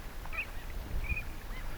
kalalokin ehkä vähän
pikkuhuittimainen ääni?
kalalokin_ehka_vahan_pikkuhuittimainen_aani.mp3